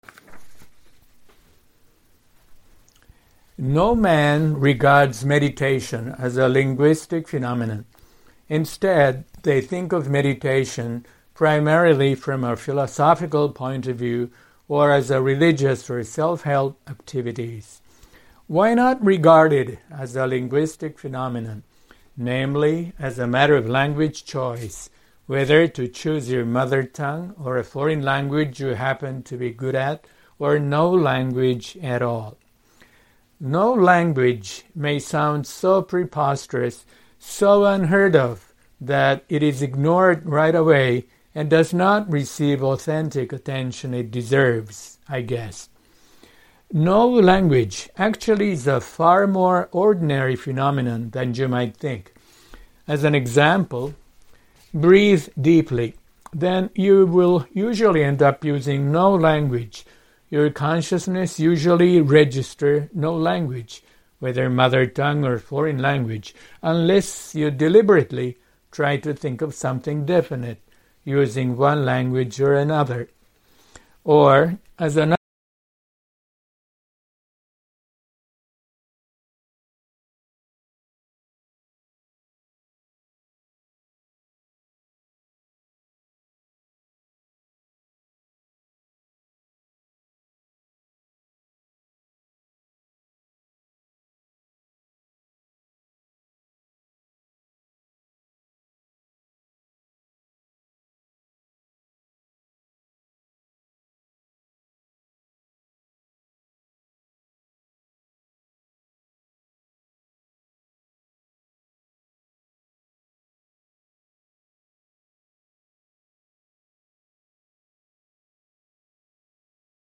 繰り返しますが、喉から上に余分な力を入れないよう、お腹からゆったり息を送って発音する、言い換えれば（オペラのようなクラシックを腹から）歌うように英語を発音する…これが英語本来のゆったりとした発音ができるようになる、私が心がけるコツです
改善例というには、おこがましいですが、私がリラックス英語にトライしたサンプルをのせます。